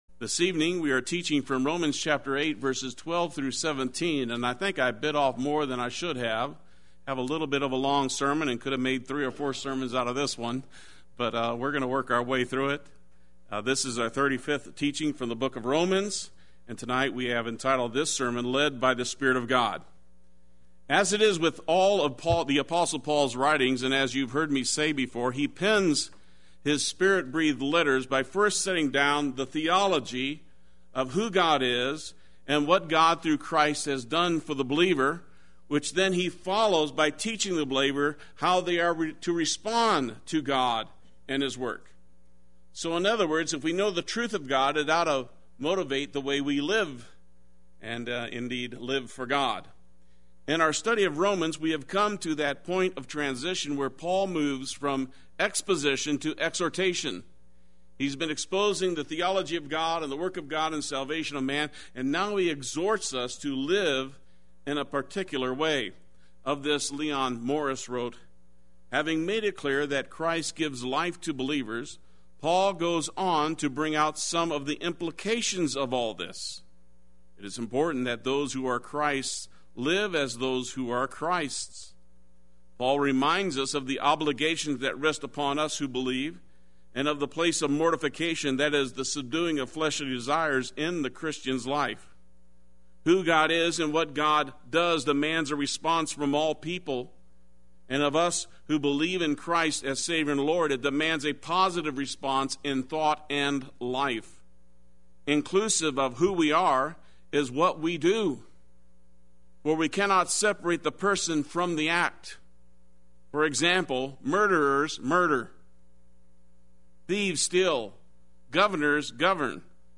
Play Sermon Get HCF Teaching Automatically.
Live By the Spirit of God Wednesday Worship